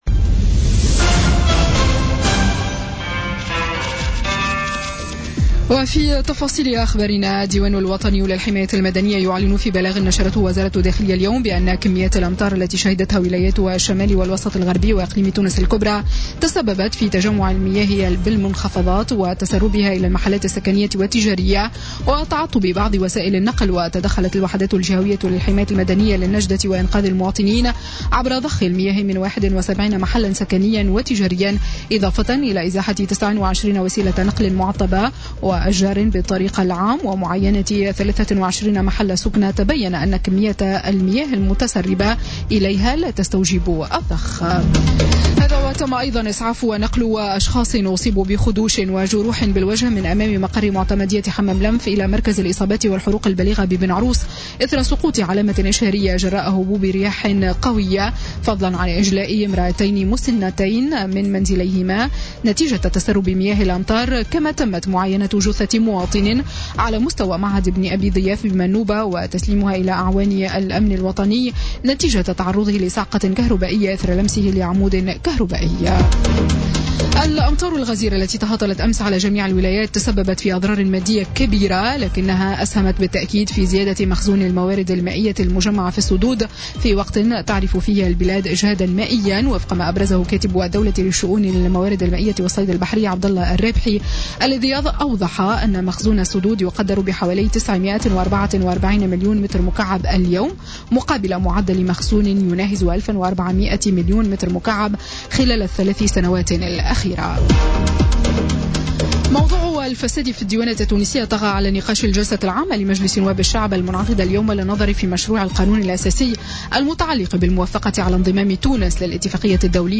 نشرة أخبار السادسة مساءً ليوم الثلاثاء 6 جوان 2017